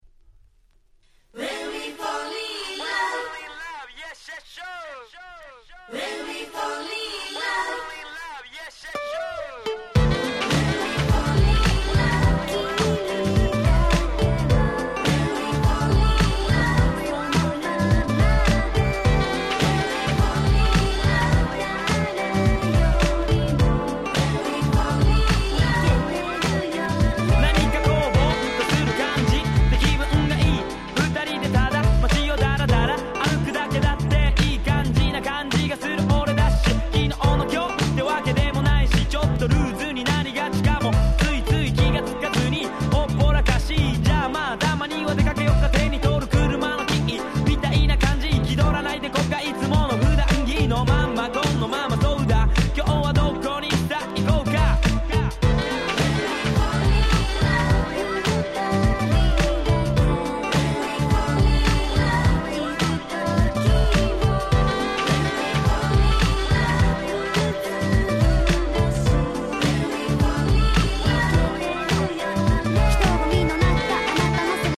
95' J-Rap Classic !!